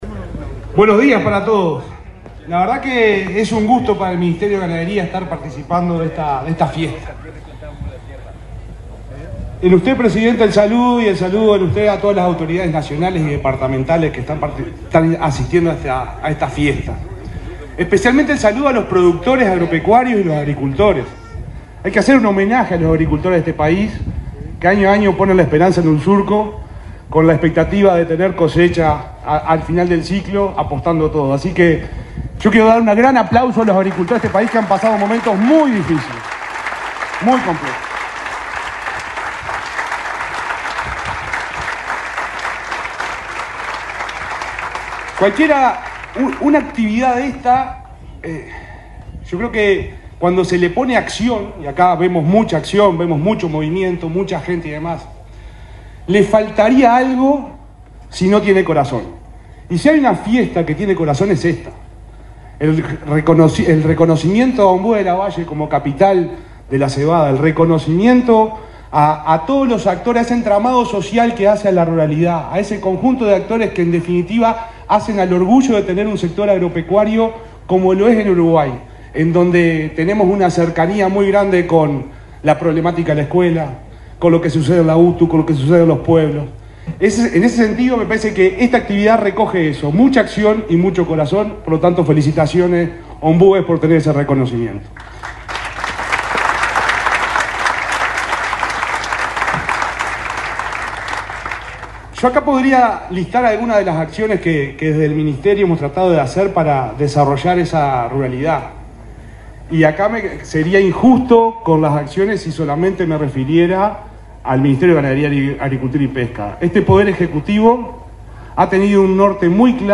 Este viernes 8, el subsecretario de Ganadería, Ignacio Buffa, se expresó durante el acto de inauguración de la cosecha de cebada en Ombúes de Lavalle,